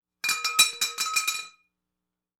Metal_22.wav